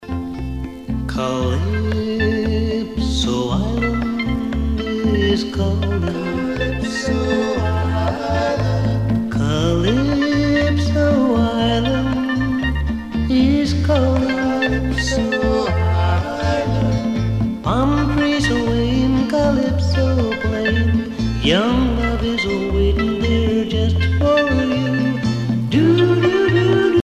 danse : calypso
Pièce musicale éditée